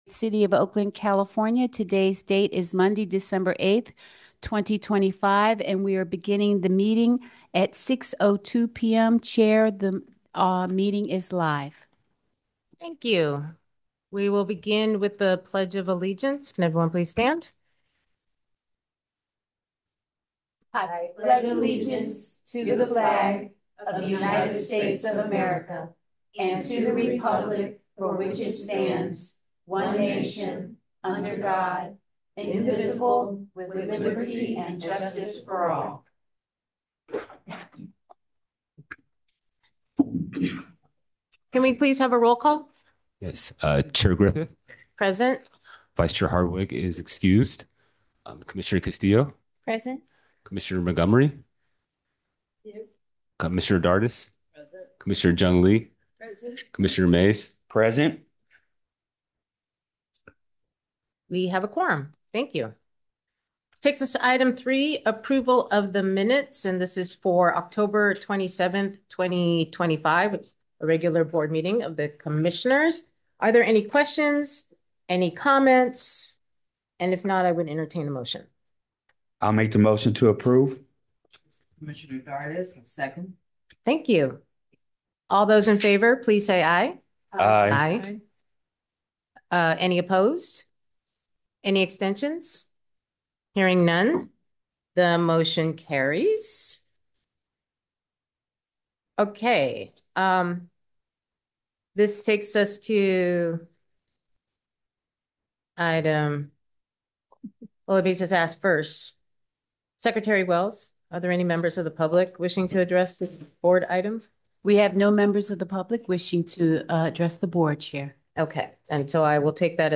Zoom-Recording-December-8-2026-Meeting.m4a